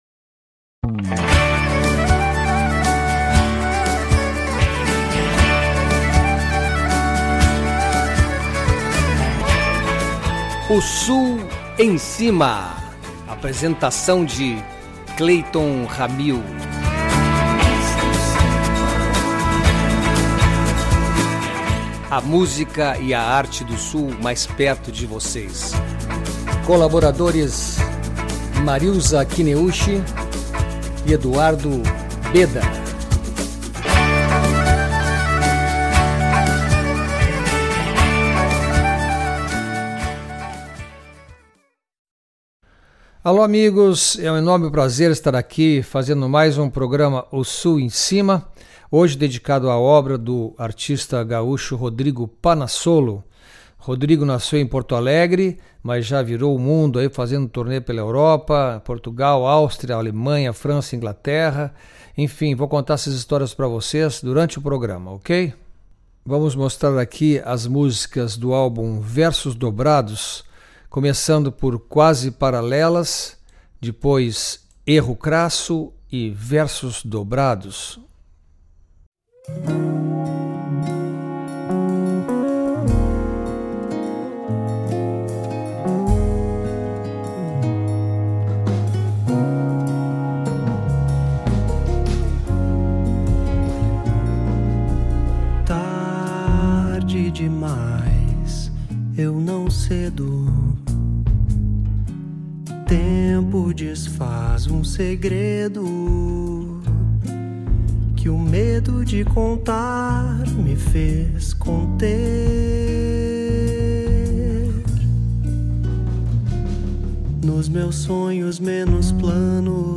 baixo acústico